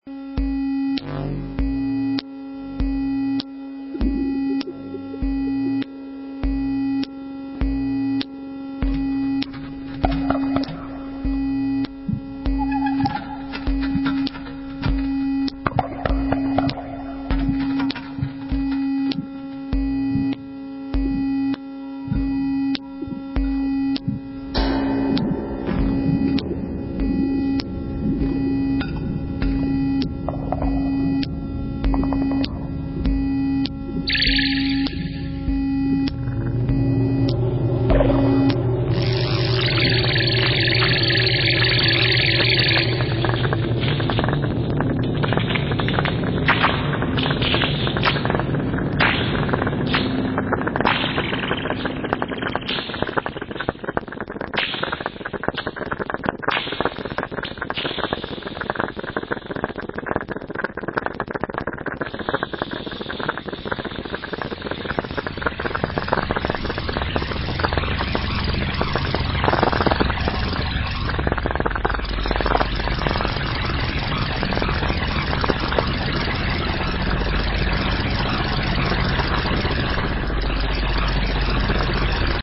percussive instruments, rotors, electronics